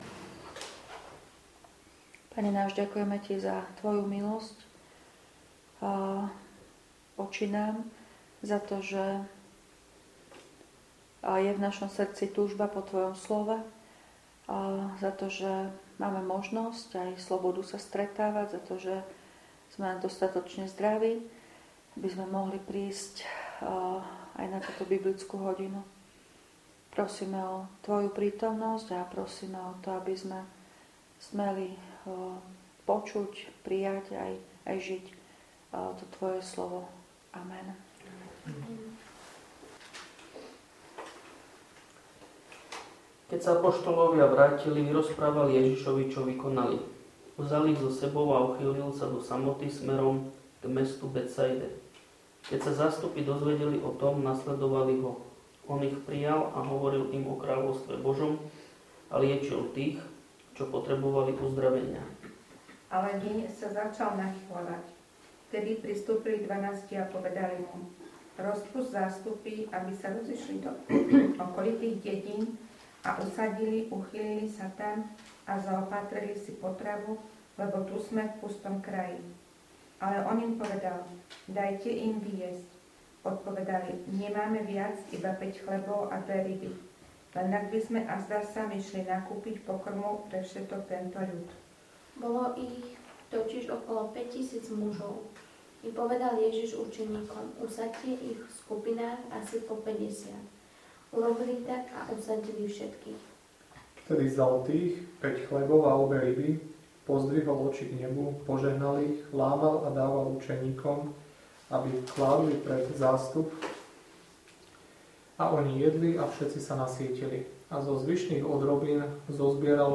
V nasledovnom článku si môžete vypočuť zvukový záznam z biblickej hodiny zo dňa 10.9.2025.